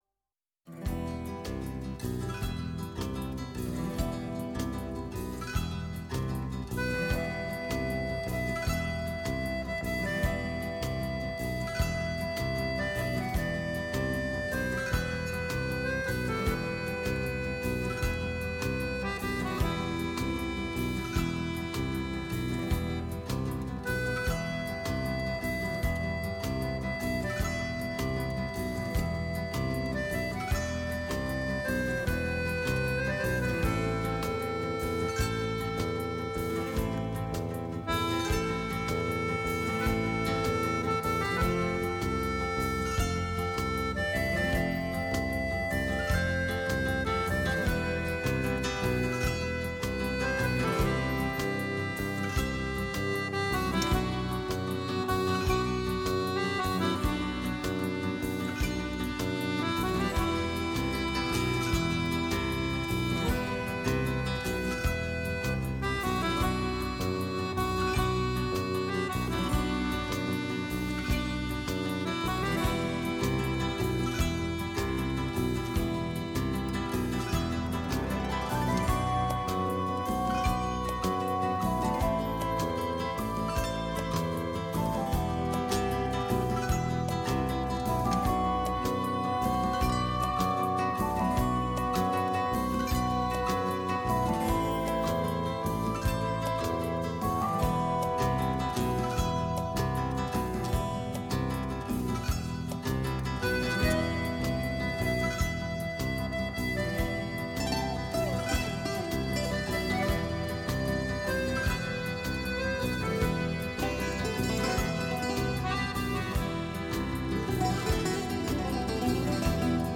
Η ΦΩΝΗ ΤΗΣ ΕΛΛΑΔΑΣ Ταξιδευοντας με Φως Ελληνικο ΜΟΥΣΙΚΗ Μουσική Πολιτισμός ΣΥΝΕΝΤΕΥΞΕΙΣ Συνεντεύξεις